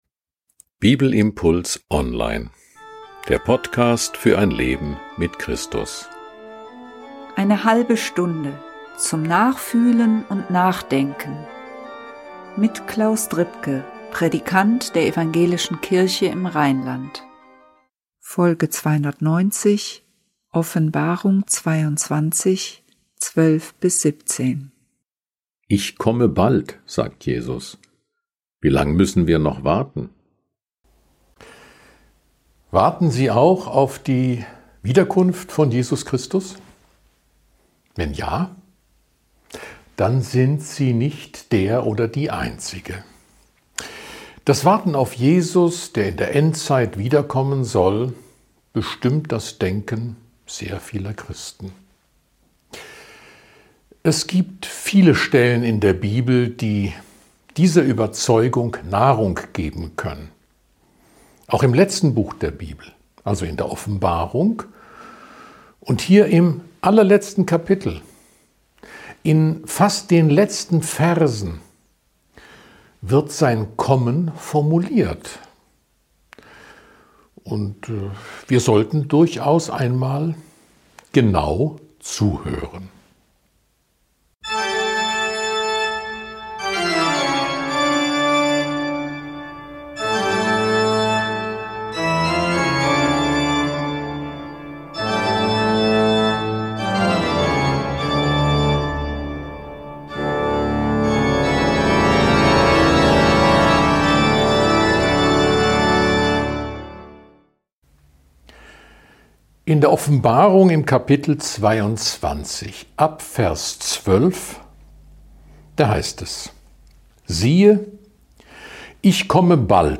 Ein Bibelimpuls zu Offenbarung 22, 12-17